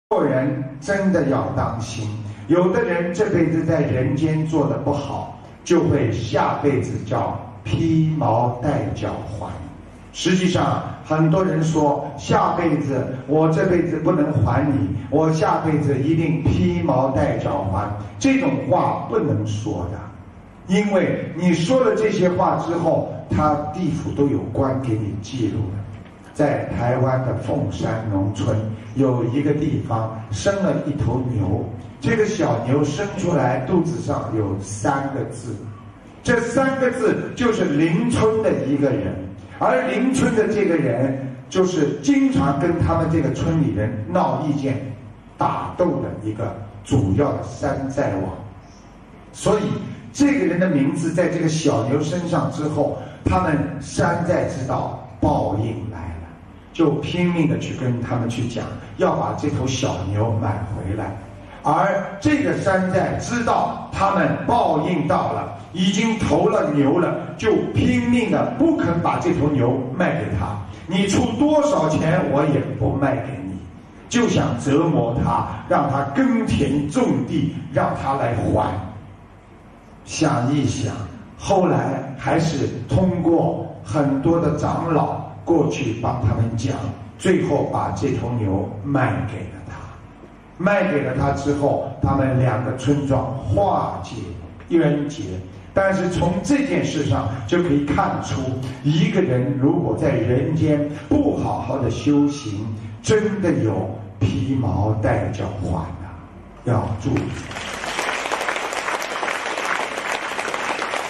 音频：做成·这样的人，下辈子·披毛戴角还·师父讲小故事大道理